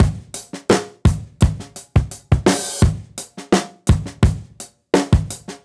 Index of /musicradar/dusty-funk-samples/Beats/85bpm
DF_BeatD_85-04.wav